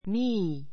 me 小 A1 miː ミー 代名詞 私を[に] ; 私 ⦣ I の目的格.